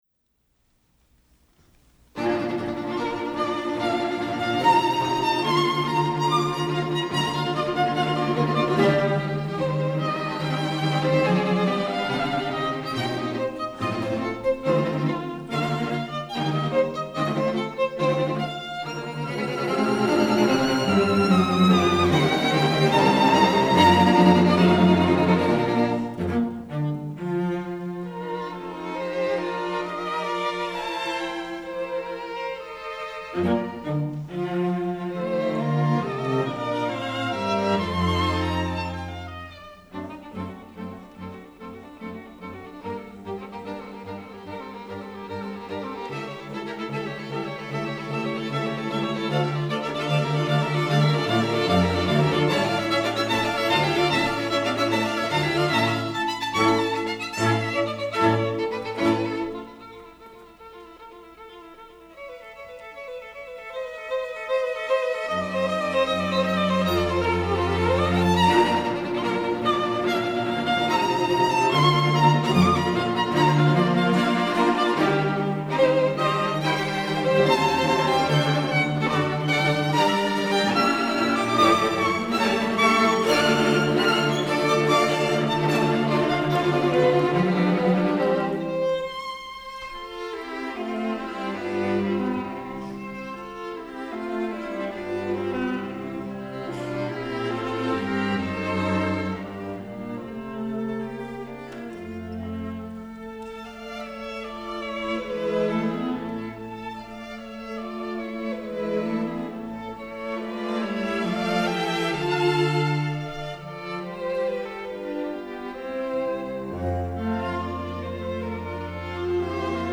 Listen to historic chamber music recordings online as heard at Vermont's Marlboro Music Festival, classical music's most coveted retreat since 1951.
String Quintet in B-flat Major, Op. 87
01_allegro_vivace.mp3